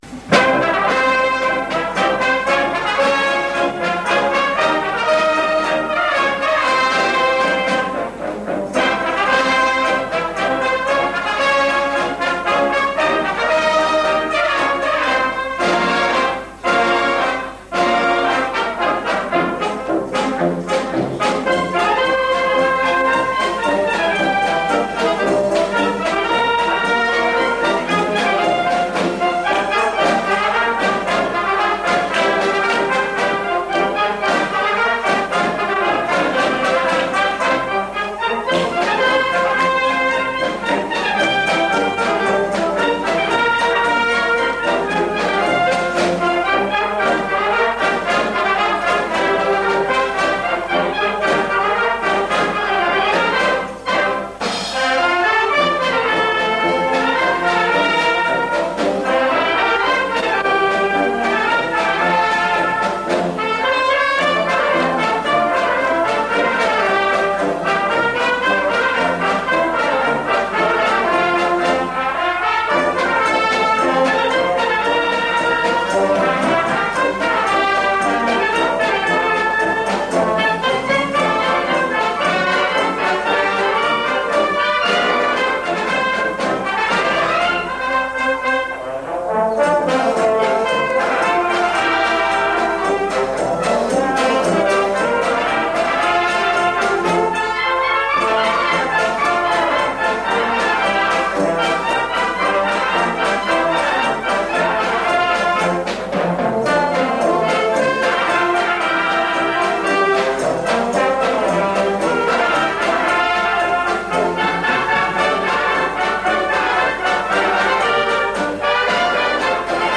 Collection : Harmonie (Feria)
Paso doble pour harmonie,
Classe d’orchestre ou banda.